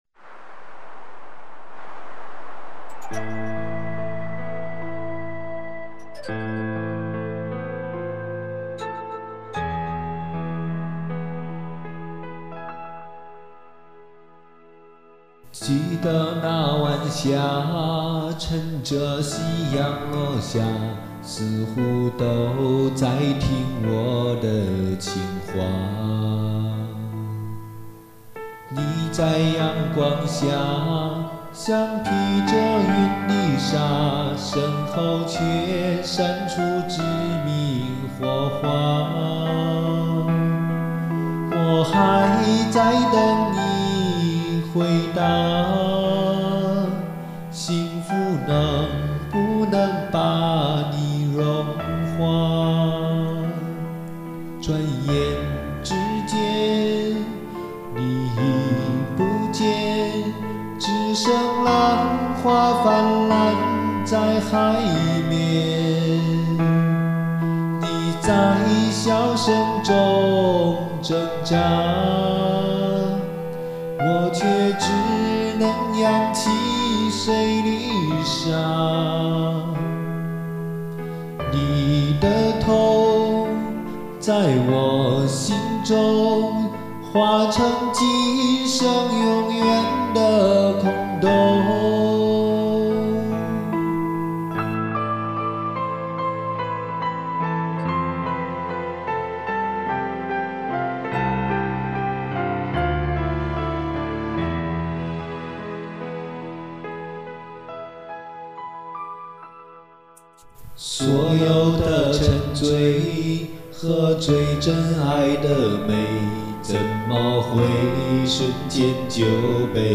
尝试新的唱法，我一个人占了三重和声，第二段。